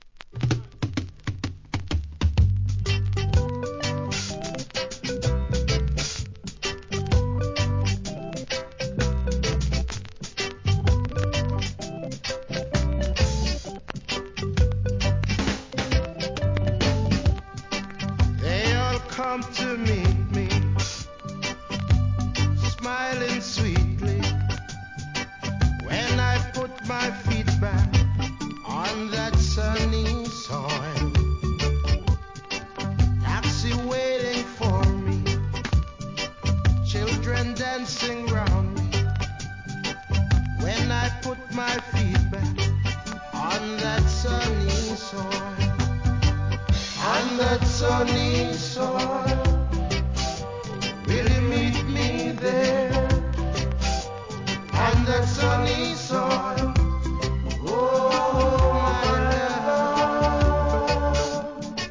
REGGAE
1976年、ほのぼのと心地よいREGGAEです♪